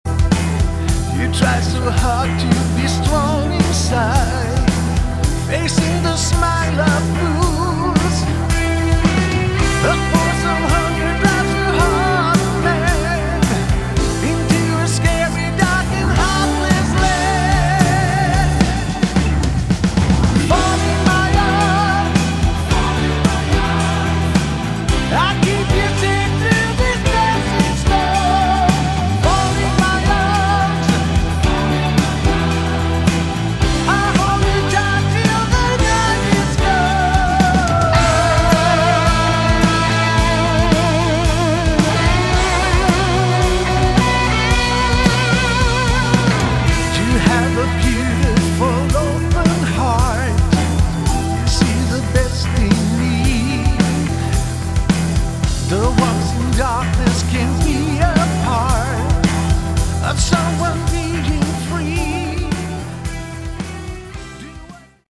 Category: AOR / Melodic Rock
vocals
guitars
drums